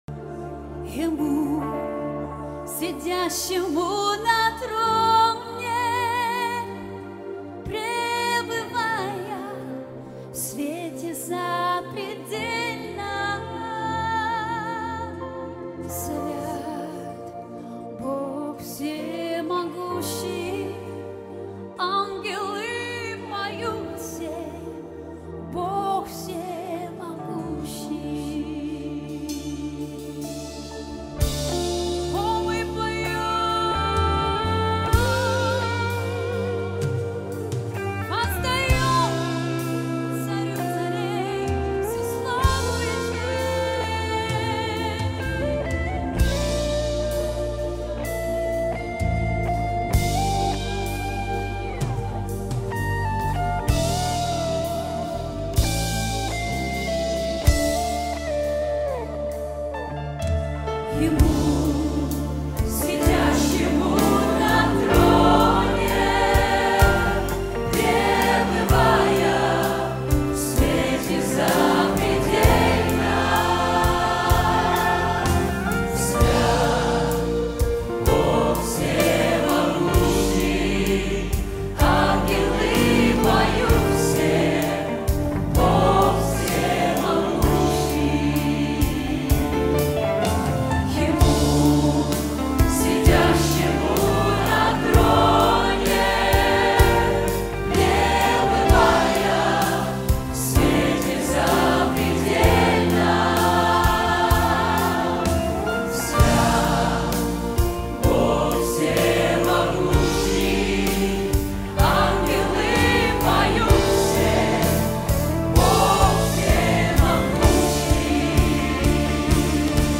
99 просмотров 71 прослушиваний 2 скачивания BPM: 127